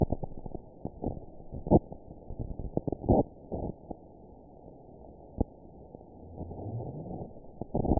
event 922157 date 12/27/24 time 18:56:05 GMT (4 months ago) score 7.64 location TSS-AB05 detected by nrw target species NRW annotations +NRW Spectrogram: Frequency (kHz) vs. Time (s) audio not available .wav